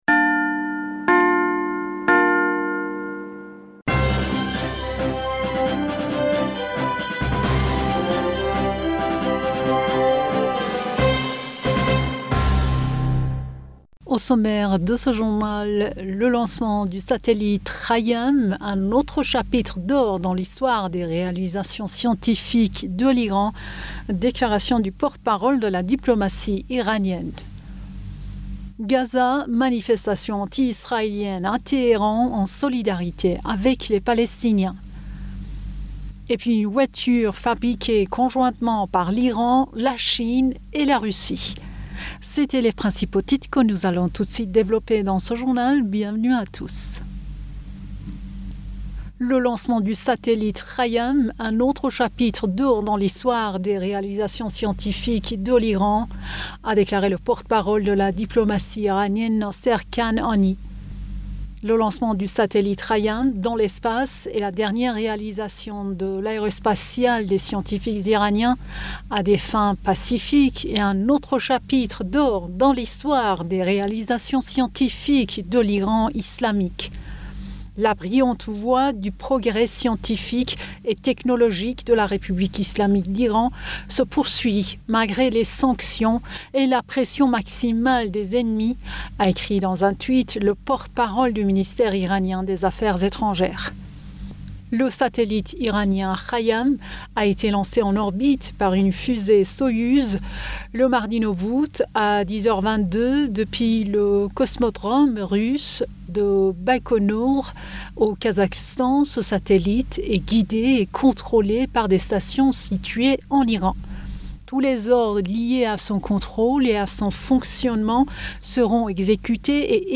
Bulletin d'information Du 10 Aoùt